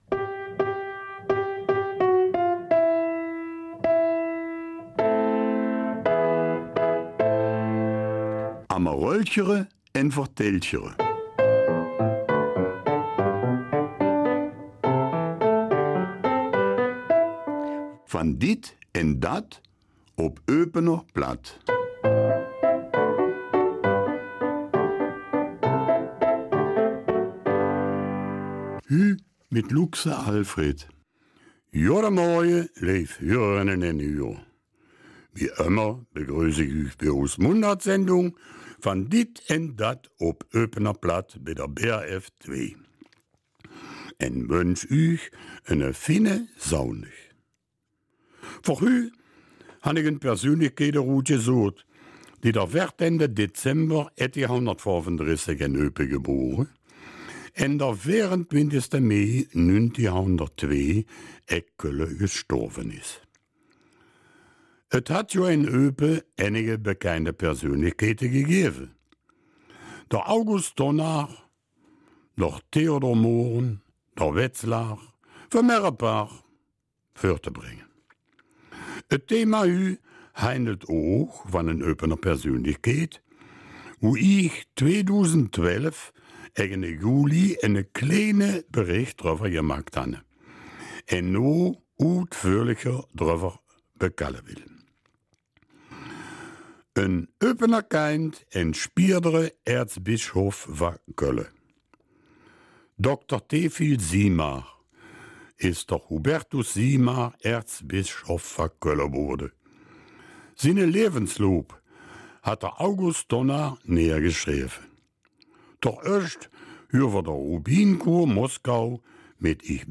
Eupener Mundart - 23. August